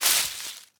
leaf_litter_step2.ogg